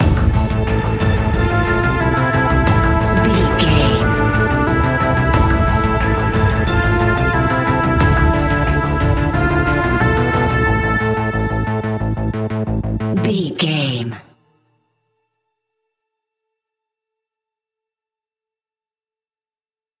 Aeolian/Minor
ominous
eerie
synthesiser
drums
horror music